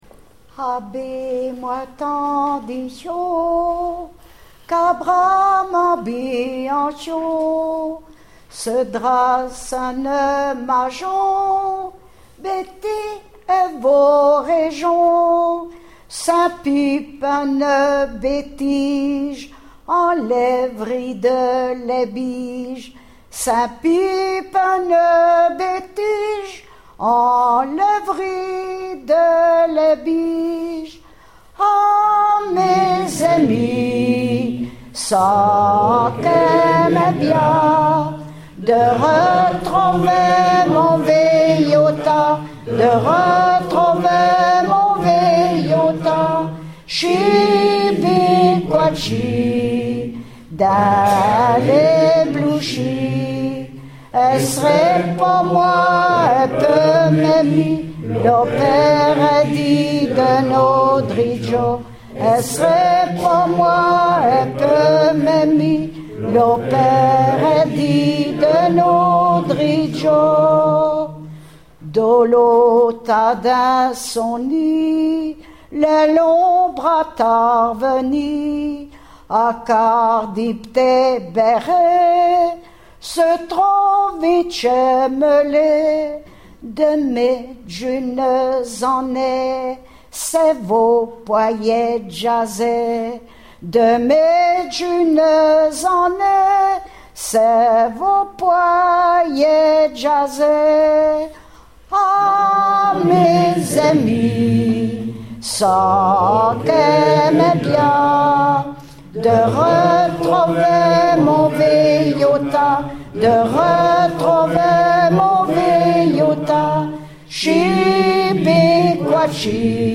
Chant interprété